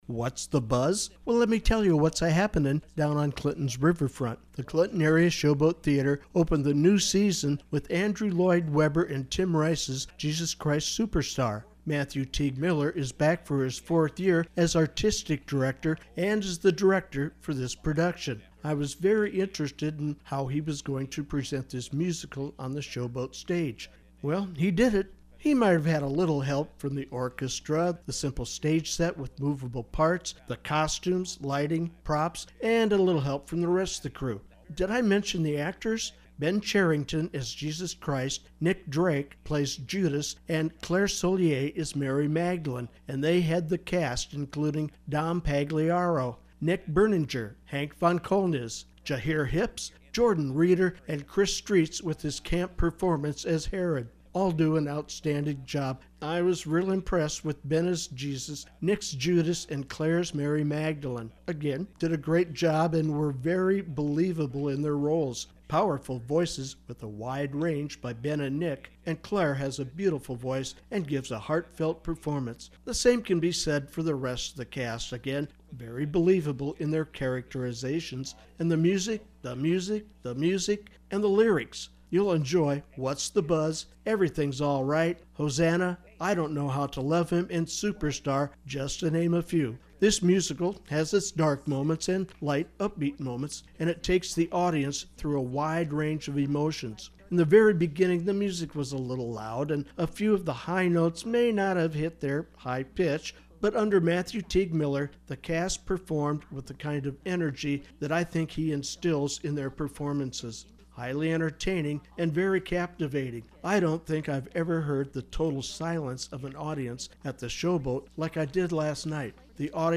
CAST-Jesus-Christ-Superstar-Review.mp3